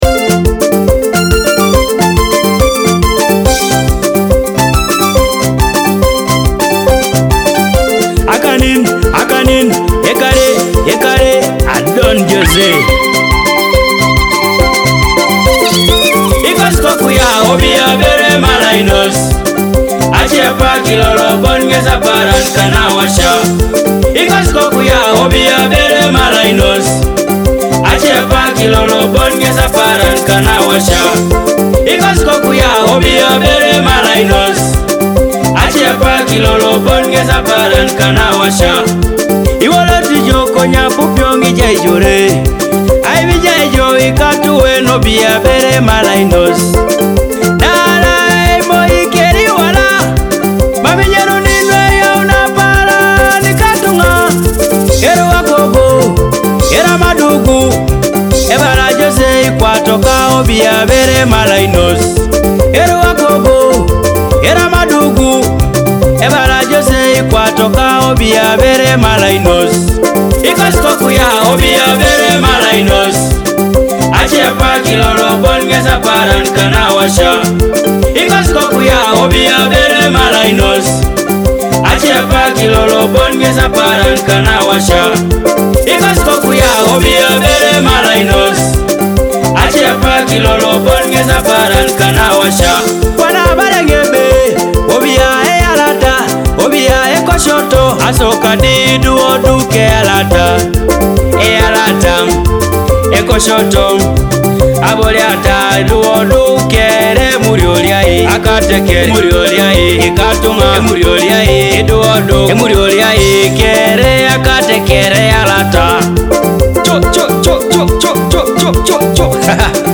Listen to and download African Music!